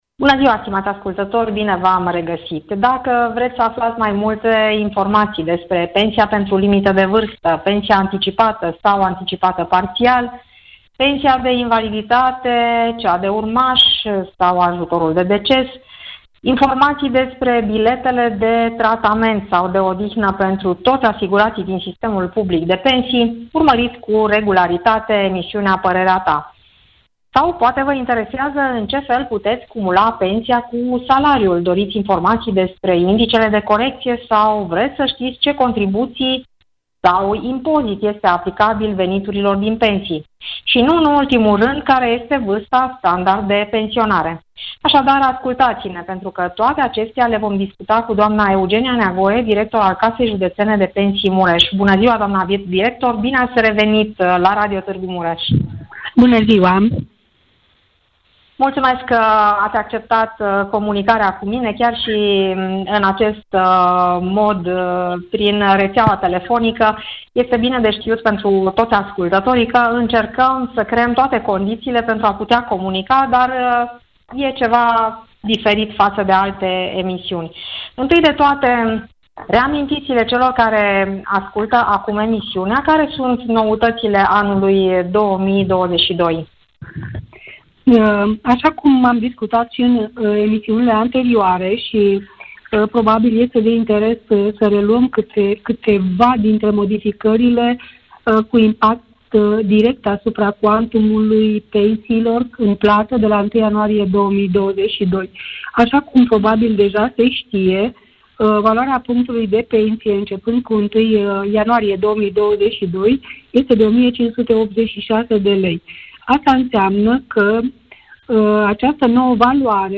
dialogul telefonic